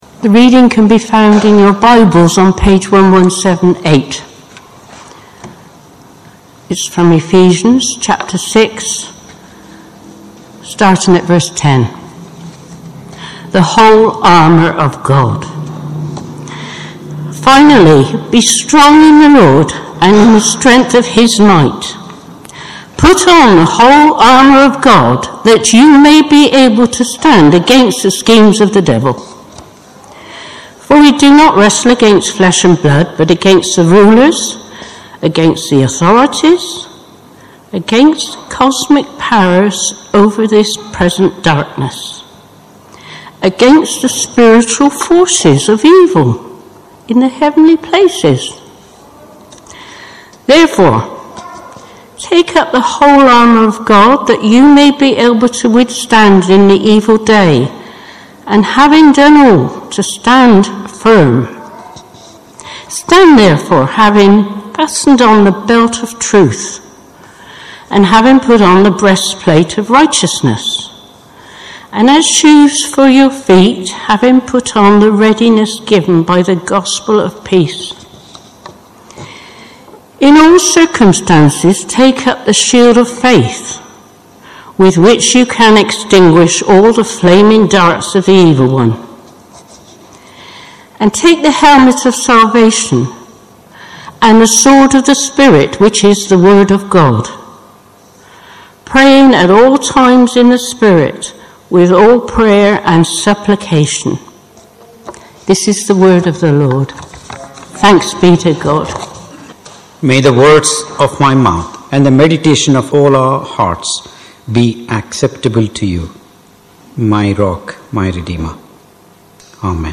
Ephesians Passage: Ephesians 6:10-18 Service Type: Morning Service Topics